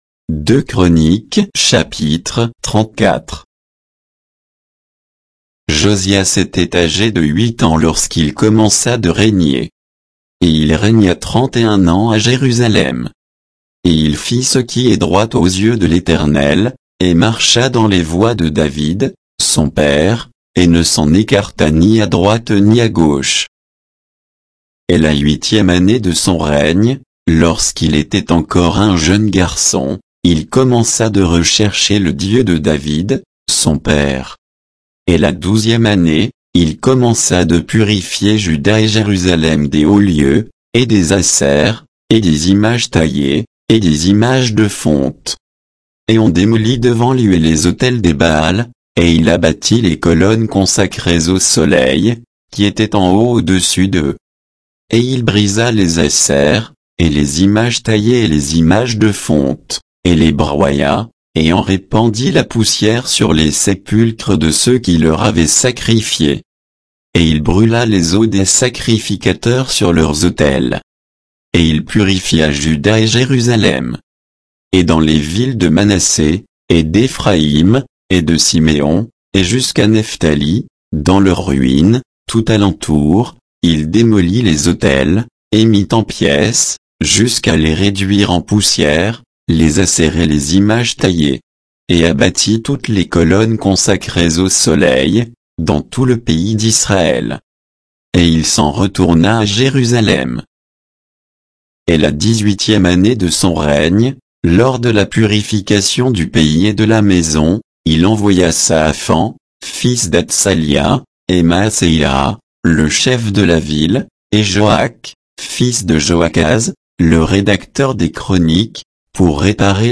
Bible_2_Chroniques_34_(texte_uniquement).mp3